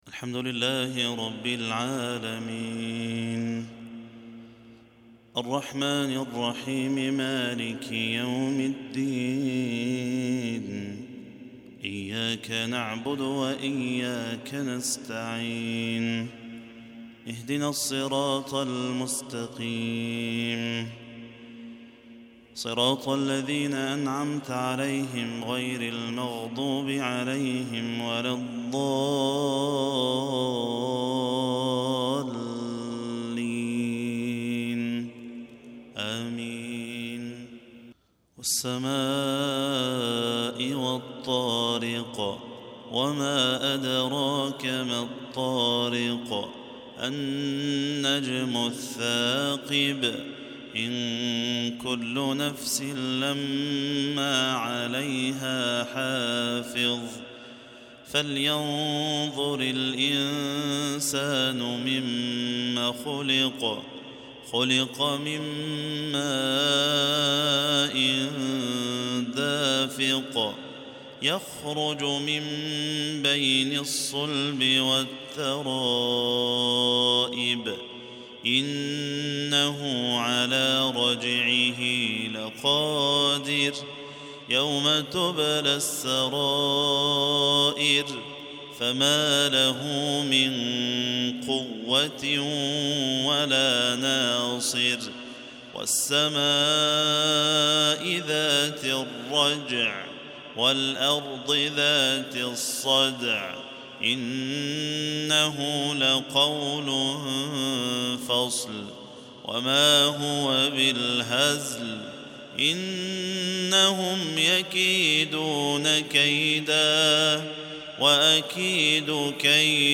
عشائية جميلة